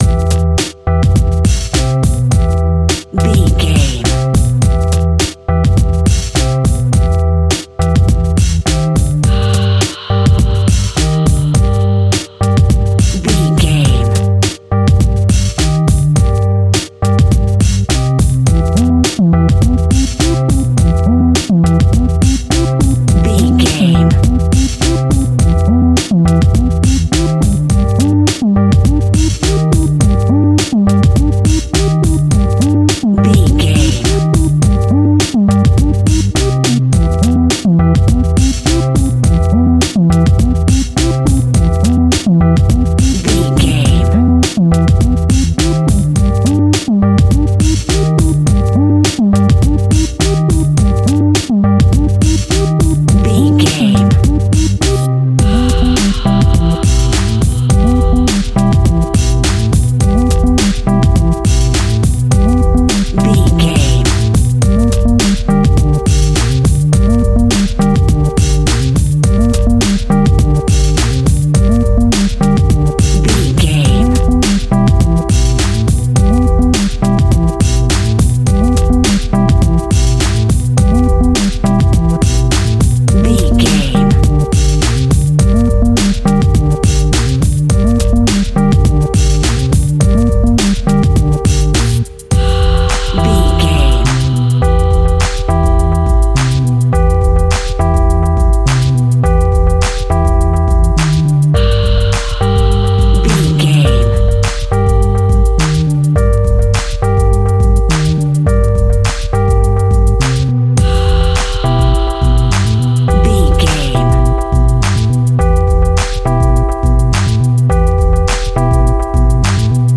Aeolian/Minor
R+B
hip hop instrumentals
downtempo
synth lead
synth bass
synth drums
turntables